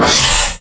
hiss.wav